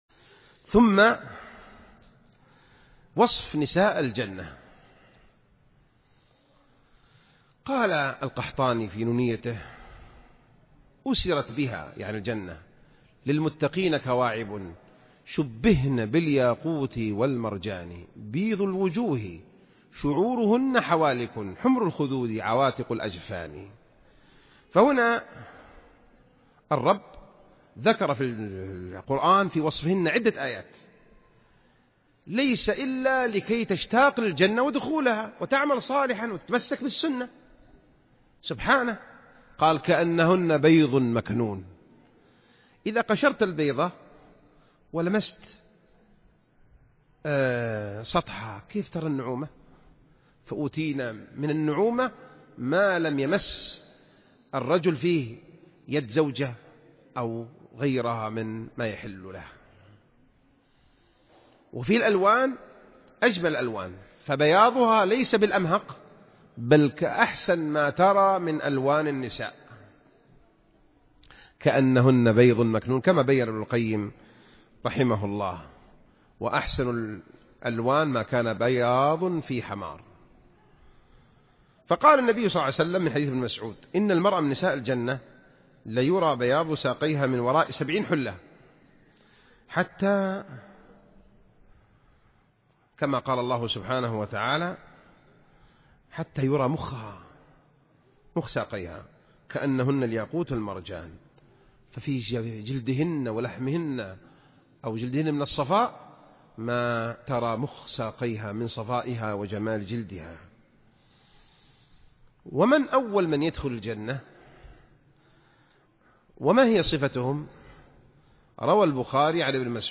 226 [ درر قحطانية ] - صفة نساء أهل الجنة { محاضرة }